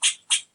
sounds_squirrel_01.ogg